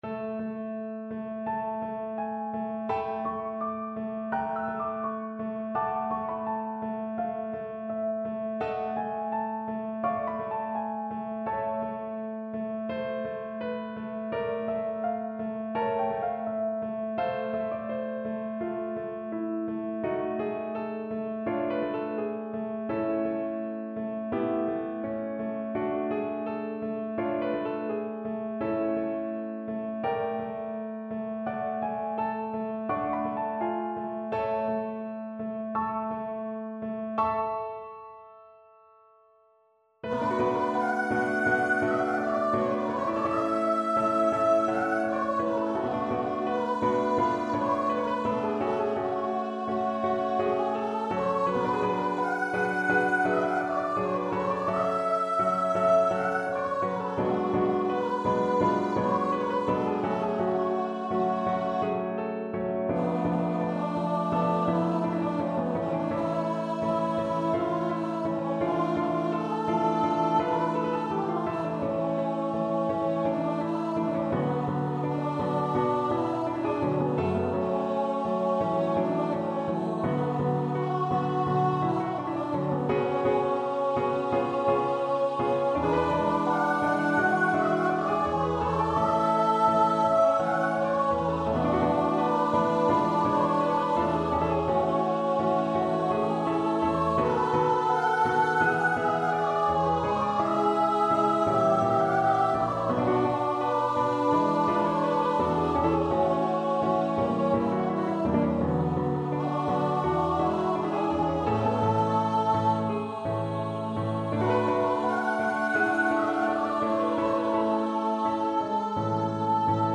Choir version
Instrument: Choir
Style: Classical